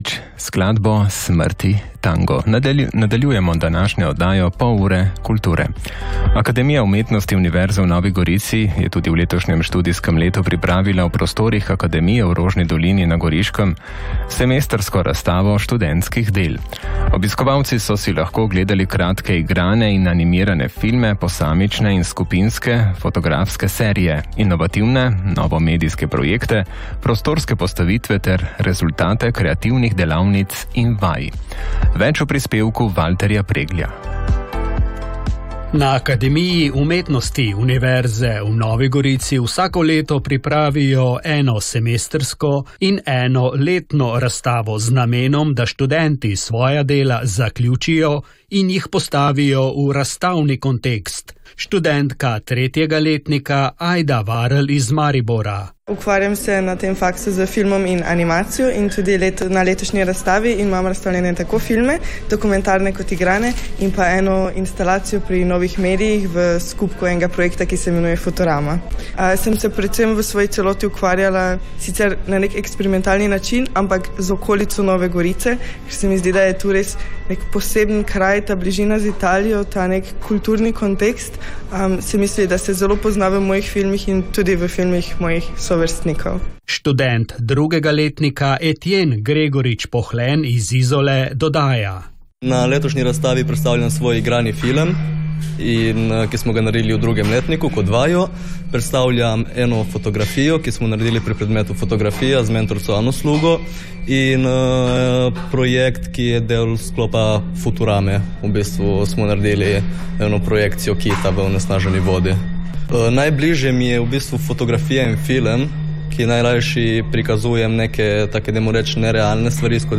Radio Koper, Popoldanski program, Razstava študentskih del, 27.1.2026, radijski prispevek: